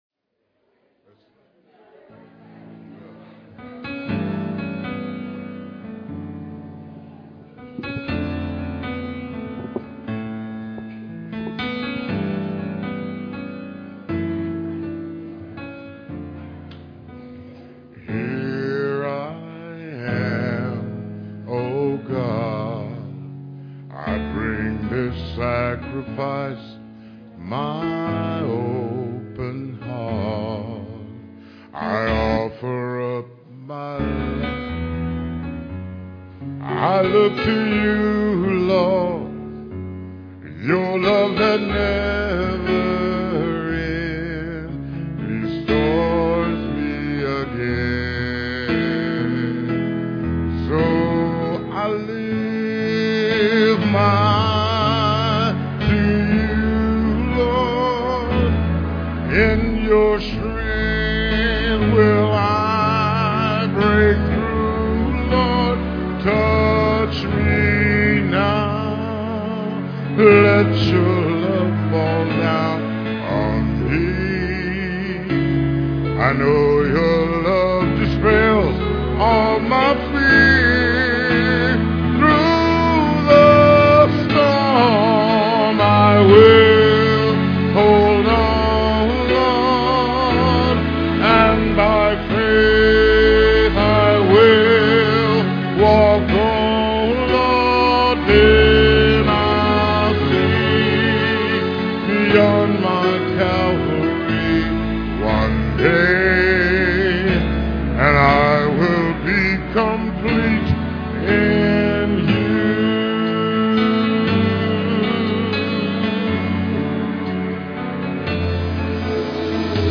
Guitar and vocal solo
Piano offertory